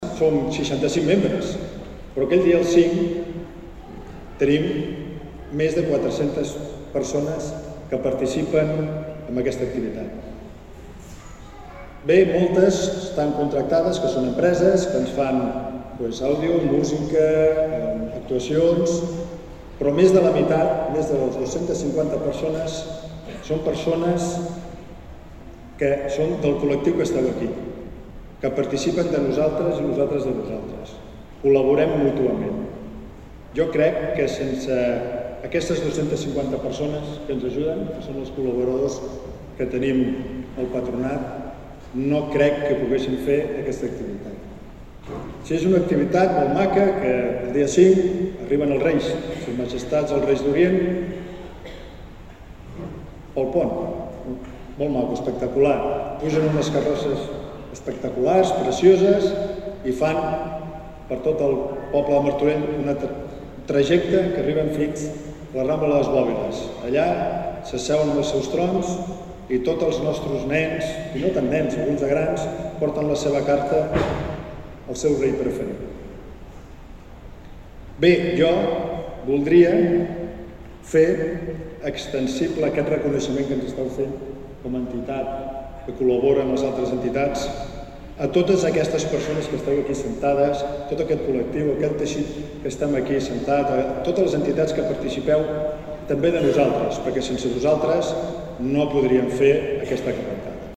La 29a Trobada d’Entitats ha reconegut l’associacionisme a Martorell aquest vespre a El Progrés, en una gala amb prop de 400 assistents.